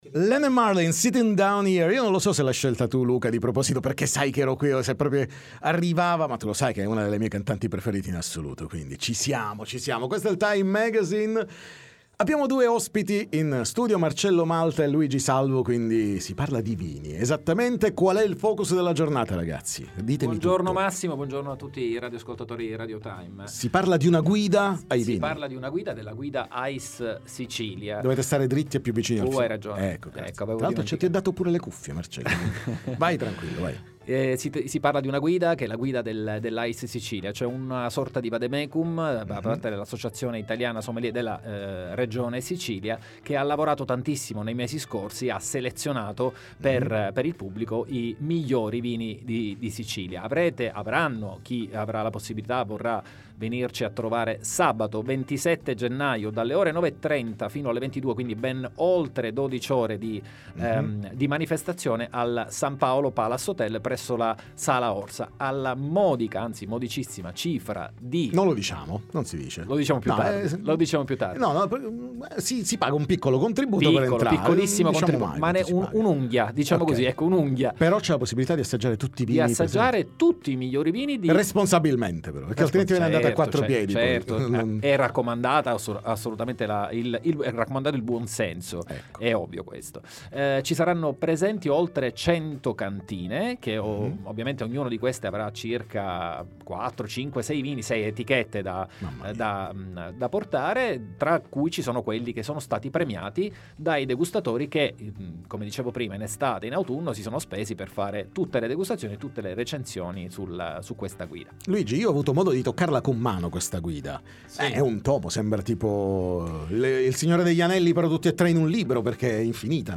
Guida ai vini di Sicilia 2024: la presentazione all’hotel San Paolo Palace, ne parliamo con gli organizzatori ai nostri microfoni